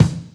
• Rich Mid-Range Bass Drum Sample E Key 190.wav
Royality free kickdrum sound tuned to the E note. Loudest frequency: 454Hz
rich-mid-range-bass-drum-sample-e-key-190-F0k.wav